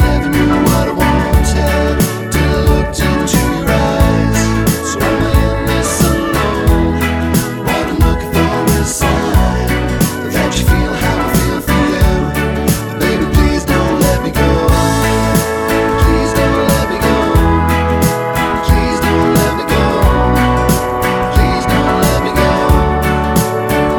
One Semitone Down Pop (2010s) 3:23 Buy £1.50